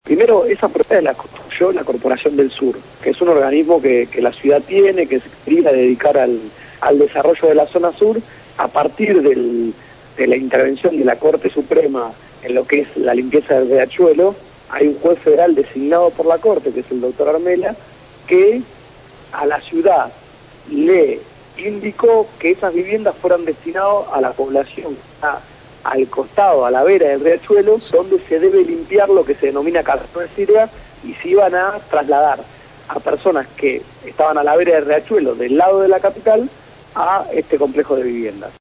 Lo dijo Gonzalo Ruanova, diputado de la Ciudad de Buenos Aires, integrante del bloque Nuevo Encuentro; en el programa «Punto de partida» (Lunes a viernes de 7 a 9 de la mañana) por Radio Gráfica.